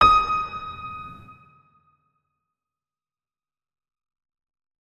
piano